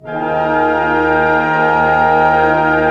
Index of /90_sSampleCDs/Roland - String Master Series/ORC_ChordCluster/ORC_Pentatonic